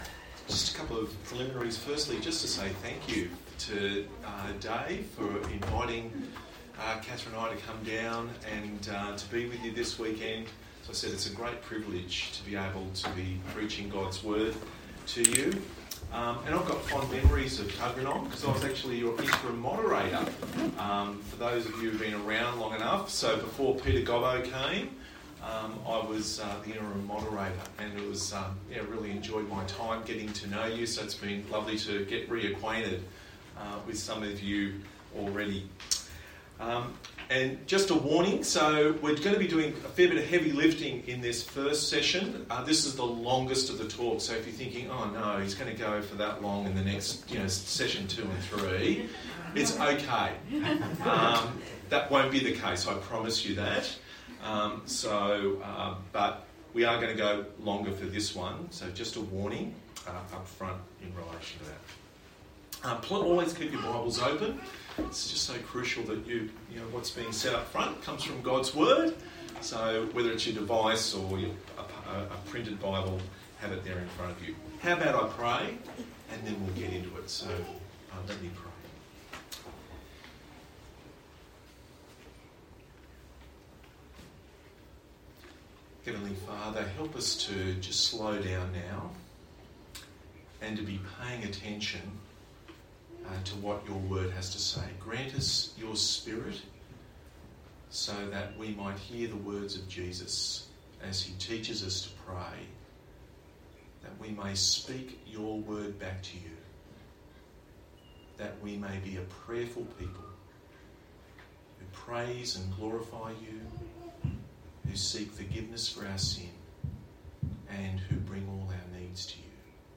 Church Camp 2023 Talk One Passage: Luke 11:1-4 Service Type: Church Camp Talk